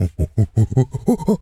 monkey_chatter_04.wav